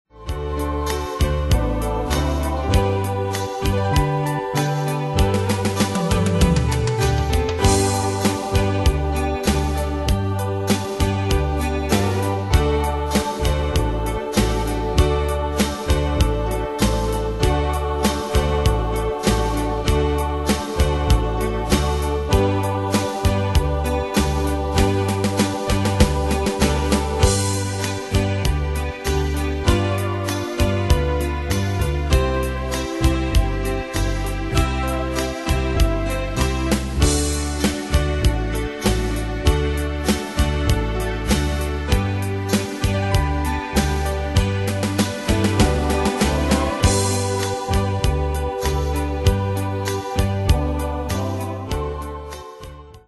Style: PopAnglo Ane/Year: 2004 Tempo: 98 Durée/Time: 3.32
Danse/Dance: Rhumba Cat Id.
Pro Backing Tracks